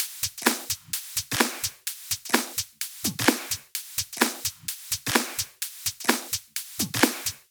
VFH3 128BPM Resistance Kit 3.wav